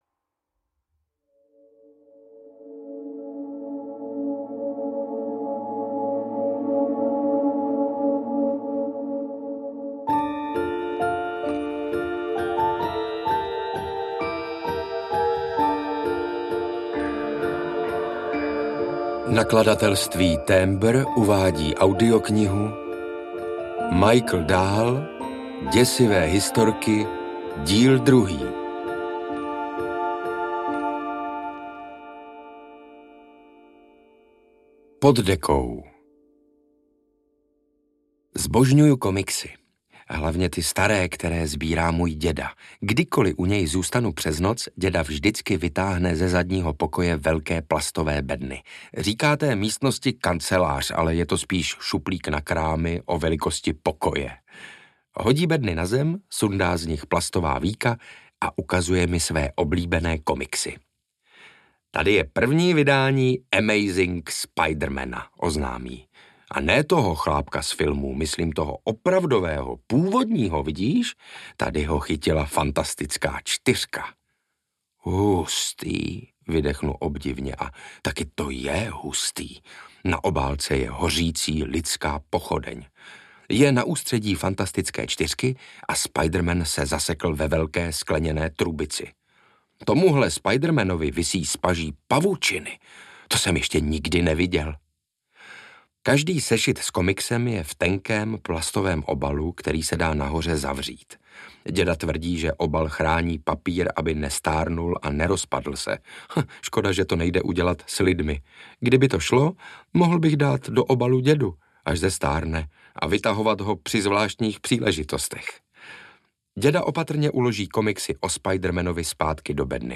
Ukázka z knihy
• InterpretSaša Rašilov